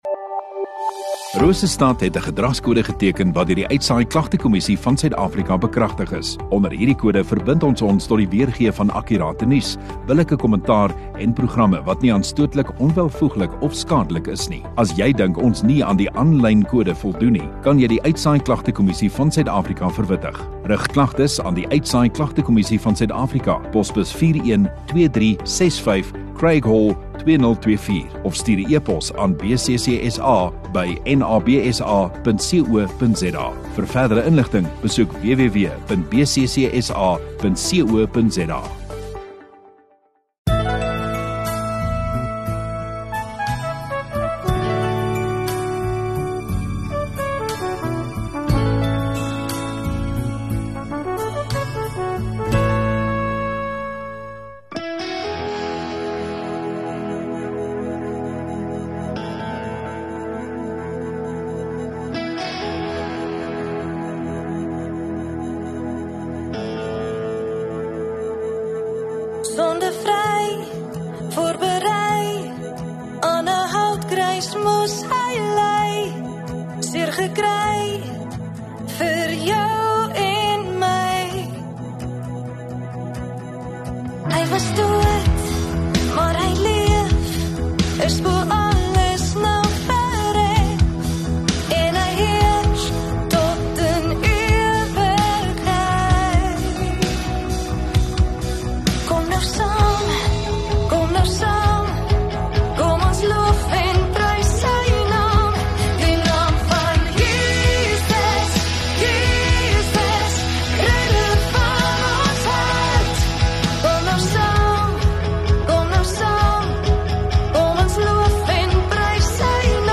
30 Jun Sondagaand Erediens